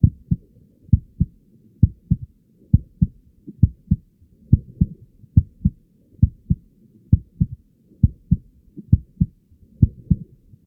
Sfx_creature_seamonkeybaby_idle_close_to_hatch_heartbeat_loop_01.ogg